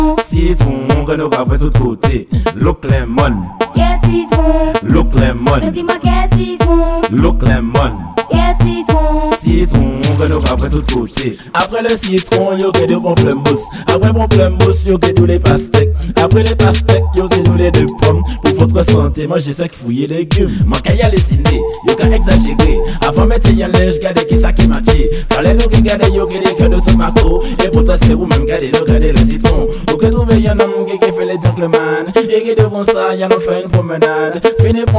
Жанр: Танцевальные
# Modern Dancehall